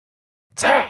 The Hollow Knight Grimm - Botón de Efecto Sonoro